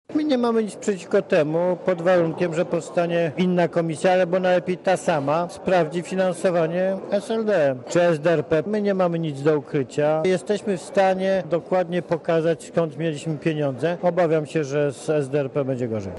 Mówi Jarosław Kaczyński (130Kb)